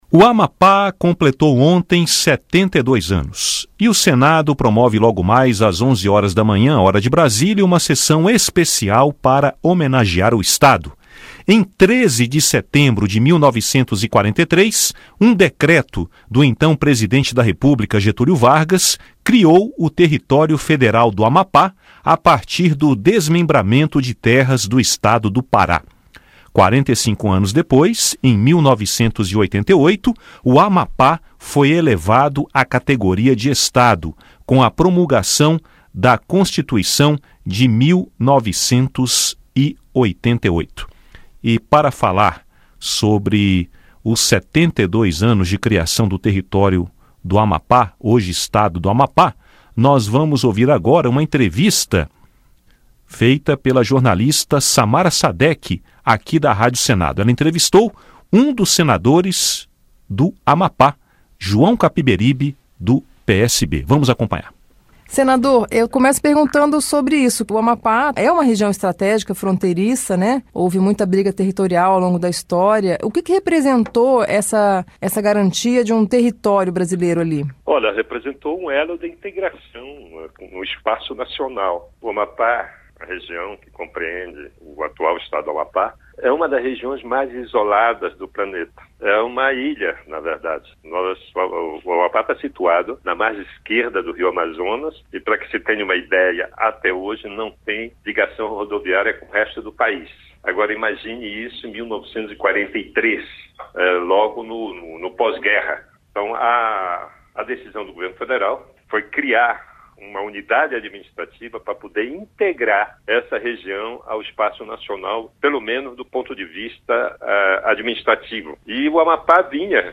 Entrevista com o senador João Capiberibe (PSB-AP).